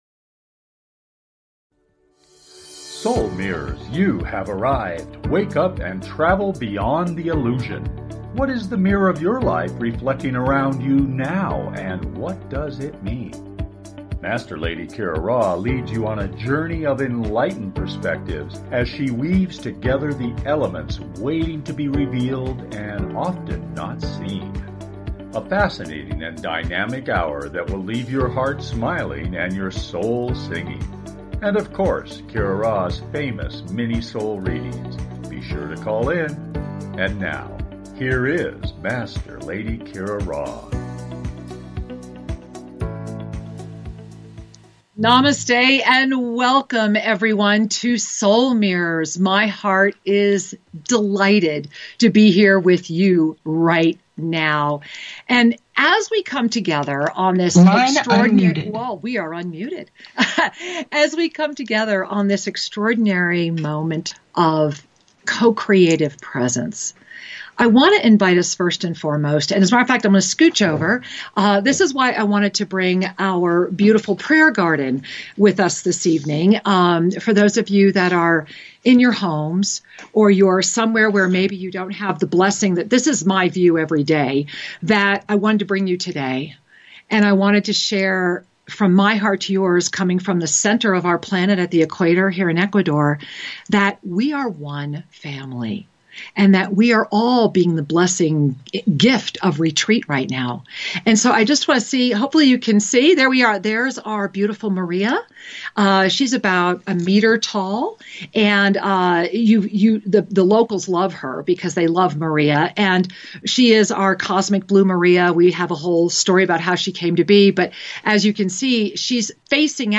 Talk Show
Lively, entertaining, and refreshingly authentic, the hour goes quickly!